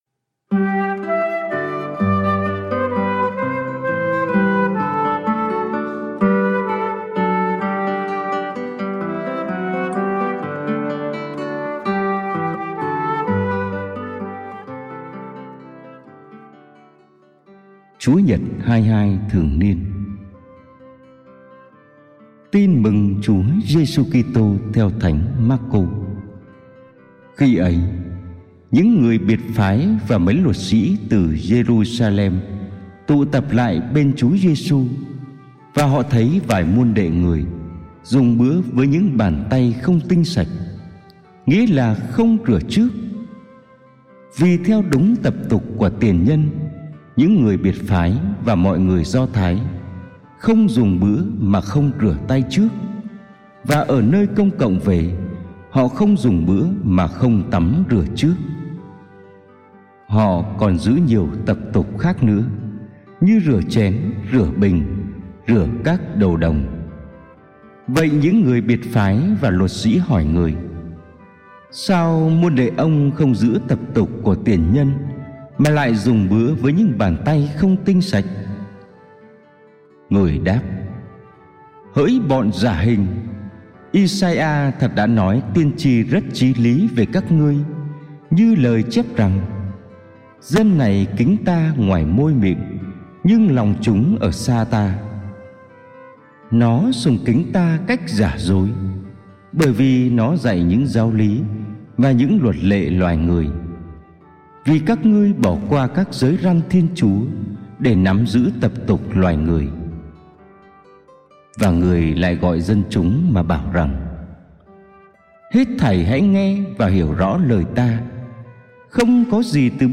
Bài giảng lễ Chúa nhật 23 thường niên B - 2021